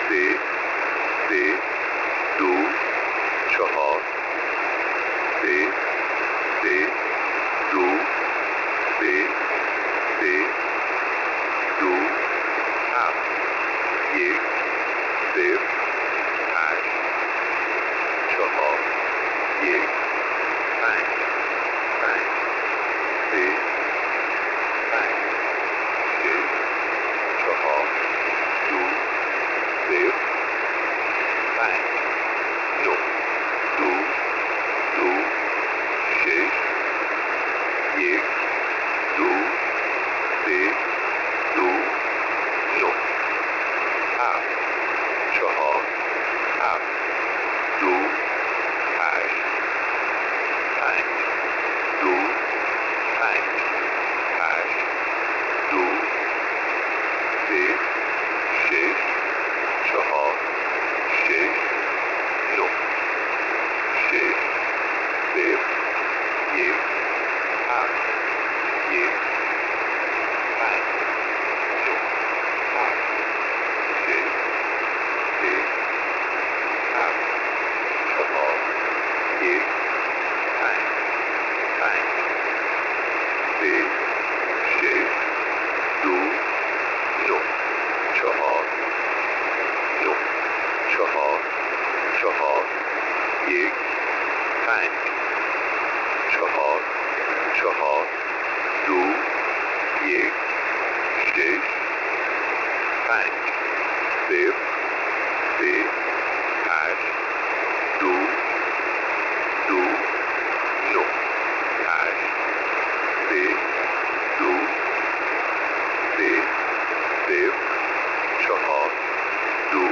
Time (UTC): 0200 Mode: USB Frequency: 7910 Recording: cyp.twrmon.net_2026-03-18T02_13_59Z_7910.00_usb.wav Waterfall Image: v32 5.PNG Comments: Clear signal on the Cyprus SDR 7910.00kHz Date (mm/dd/yy): 03/17/2026